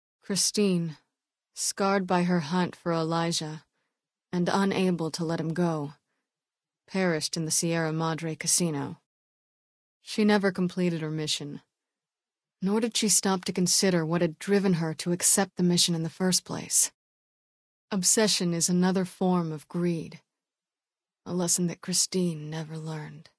Category:Dead Money endgame narrations Du kannst diese Datei nicht überschreiben. Dateiverwendung Die folgende Seite verwendet diese Datei: Enden (Dead Money) Metadaten Diese Datei enthält weitere Informationen, die in der Regel von der Digitalkamera oder dem verwendeten Scanner stammen.